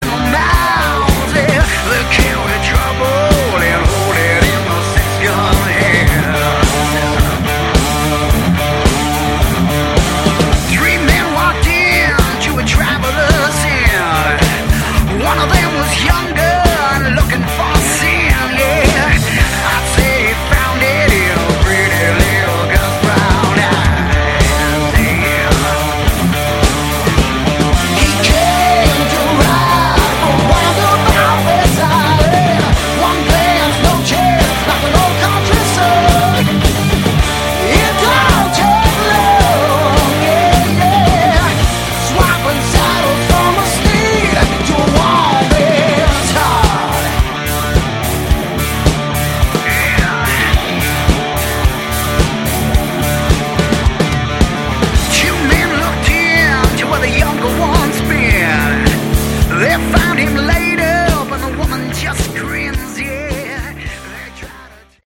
Category: Hard Rock
bass
vocals
keyboards
guitar
drums